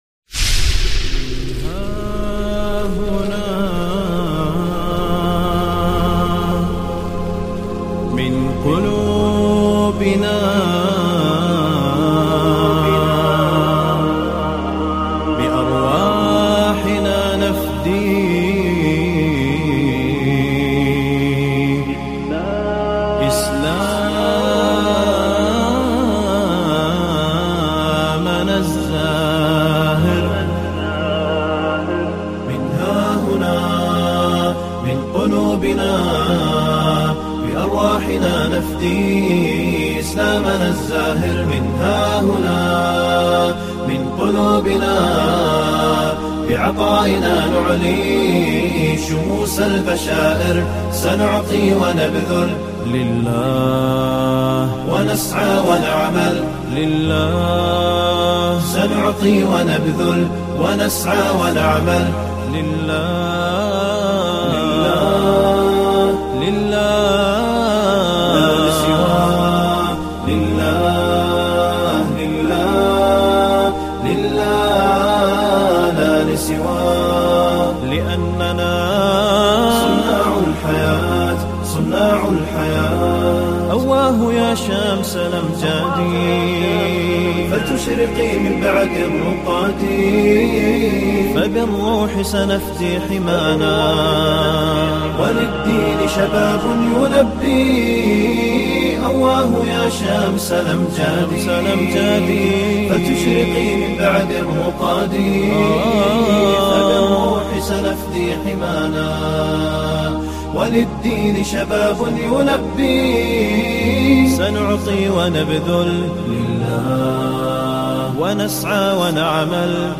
Listen to this beautiful Islamic Nasheed.